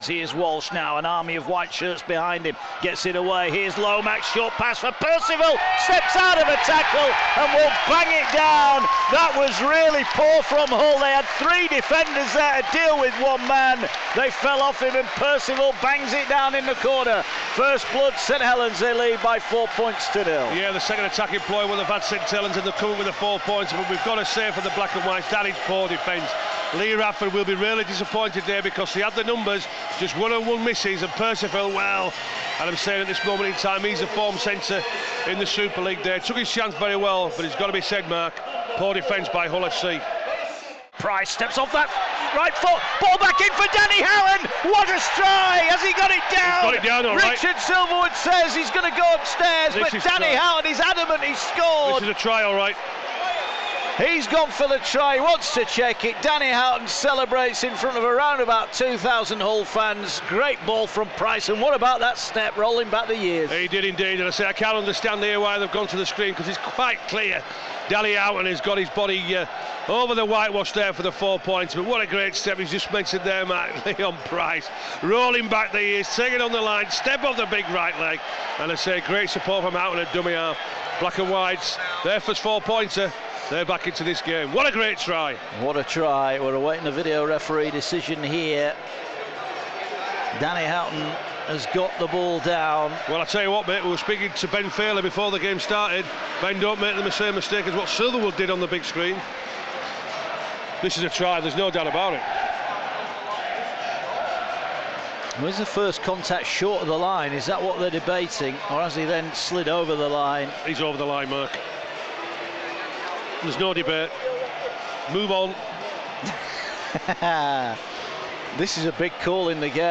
Rugby League LIVE: St. Helens 18 Hull FC 47